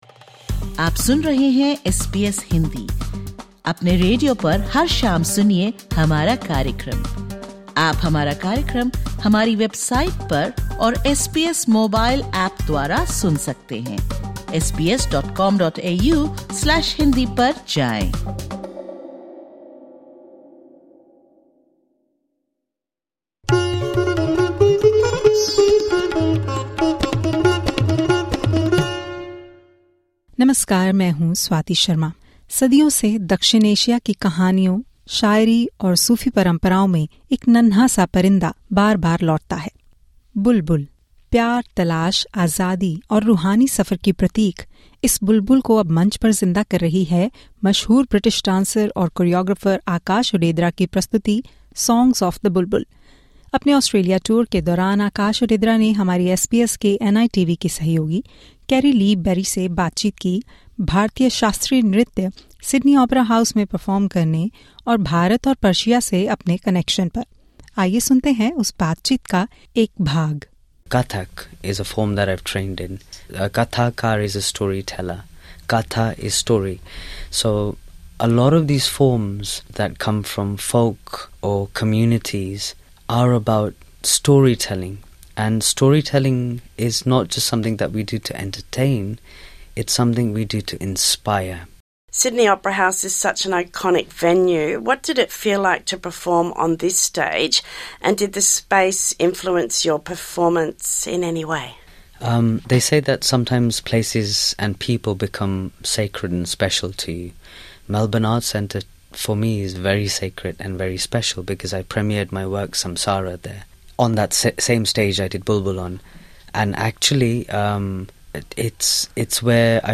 British-Indian dancer Aakash Odedra draws on that imagery in his production 'Songs of the Bulbul', which was performed at The Arts Centre Melbourne during his Australian tour. He spoke to SBS's NITV about Kathak, cross-cultural influences and the responsibility of artists working within classical traditions today.